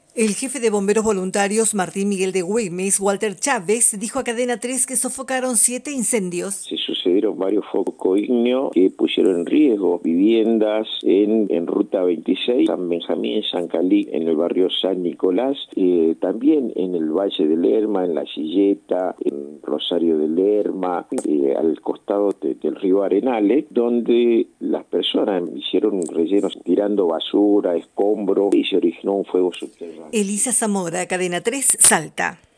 Bomberos controlaron siete focos de incendio en Salta - Boletín informativo - Cadena 3 - Cadena 3 Argentina